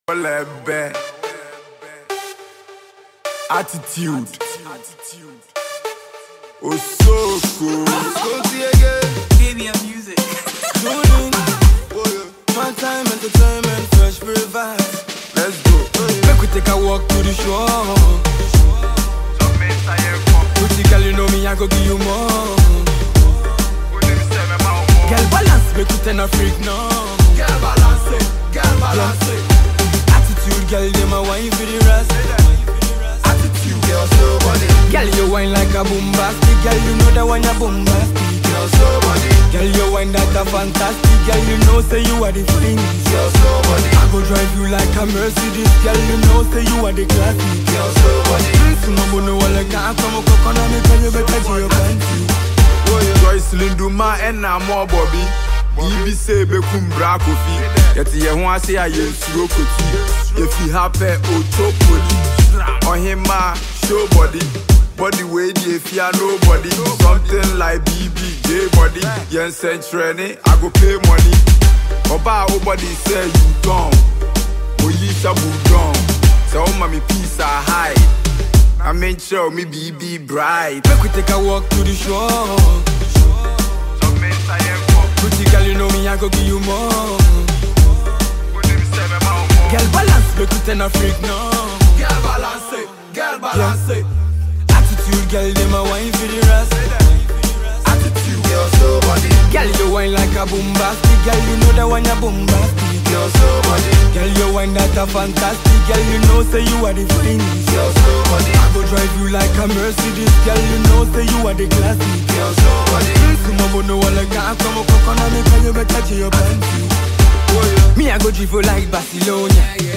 dancehall titan